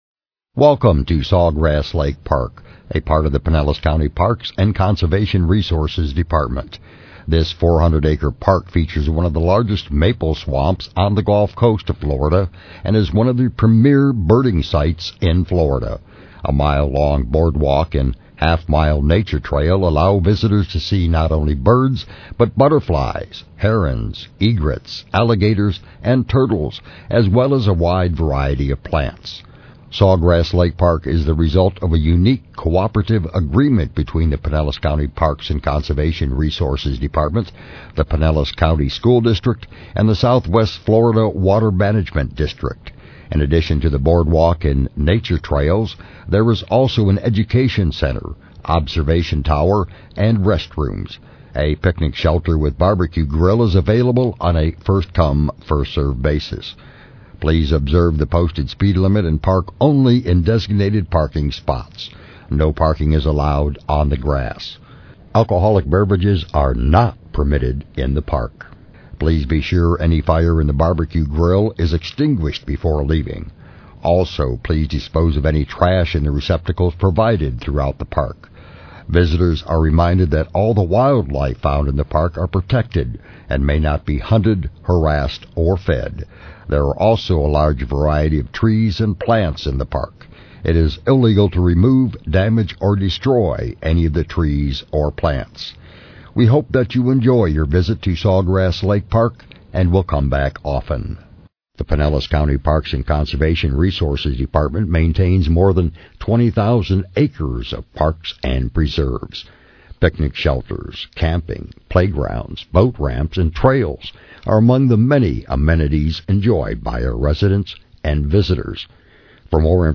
Audio Tour Park Finder